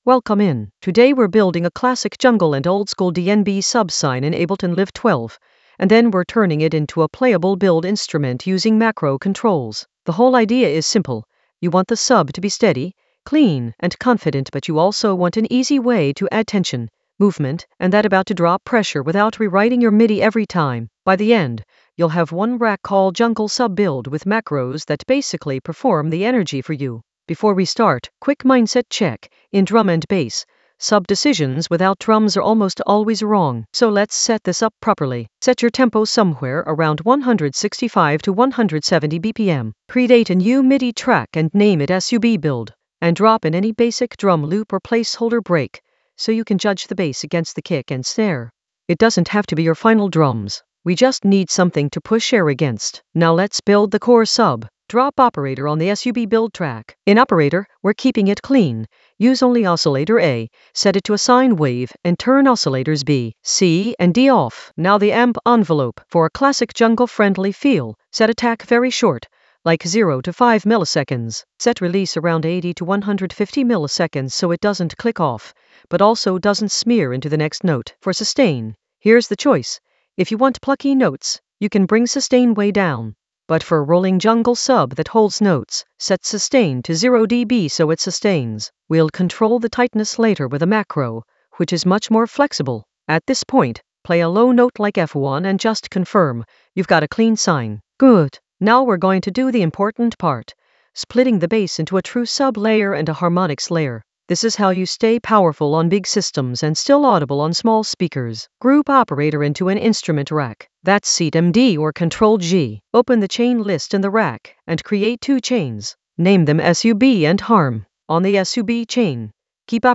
Narrated lesson audio
The voice track includes the tutorial plus extra teacher commentary.
An AI-generated beginner Ableton lesson focused on Subsine build tutorial using macro controls creatively in Ableton Live 12 for jungle oldskool DnB vibes in the Basslines area of drum and bass production.